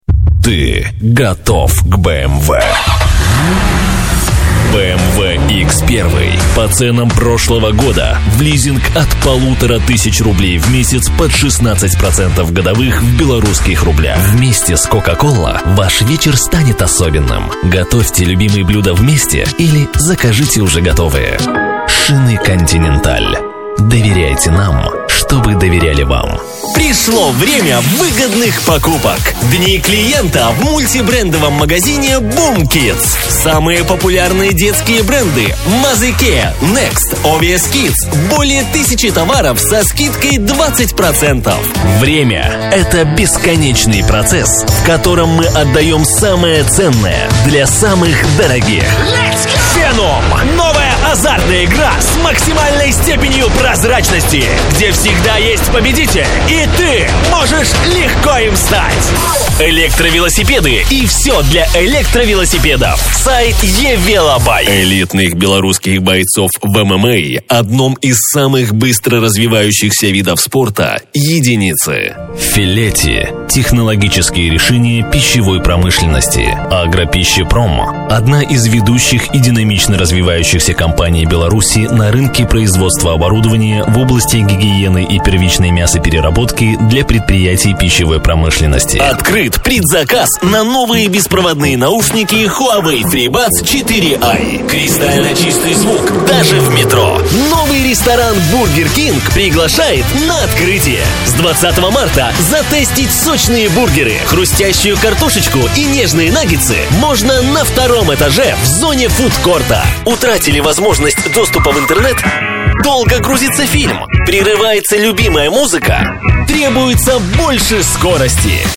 Молодой динамичный голос, но могу быть серьёзным :) График работы: Пн.
Тракт: микрофон: Neumann TLM 103,предусилитель: dbx 376,конвертор: RME Babyface Pro